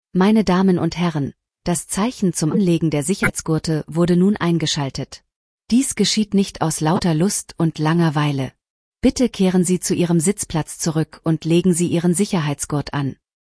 FastenSeatbelt.ogg